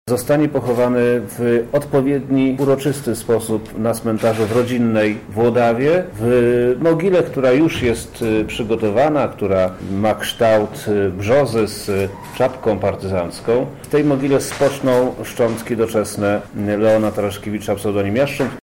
– mówi Przemysław Czarnek, wojewoda lubelski